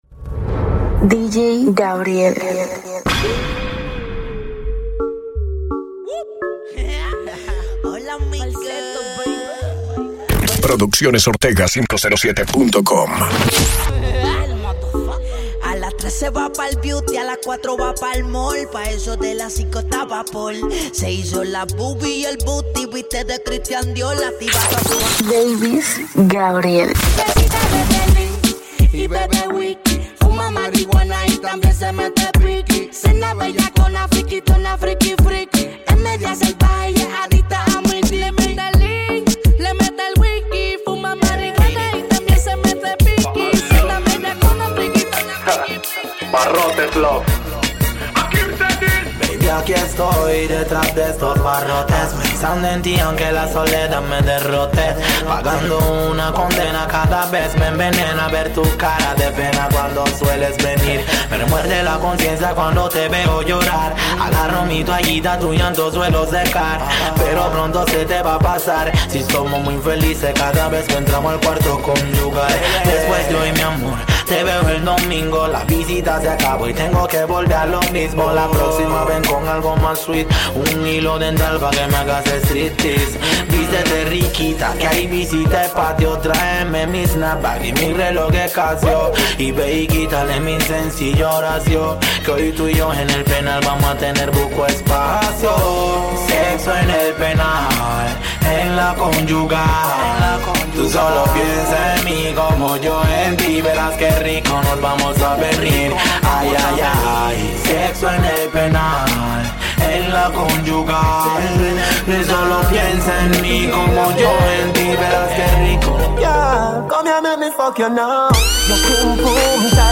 Mixes | Reggae